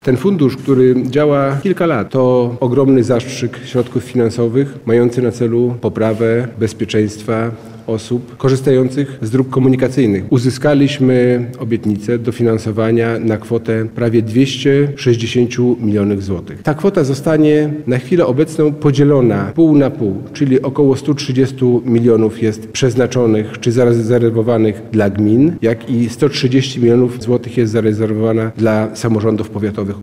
– Jeden samorząd może złożyć maksymalnie dwa wnioski – mówi wicewojewoda lubelski Robert Gmitruczuk.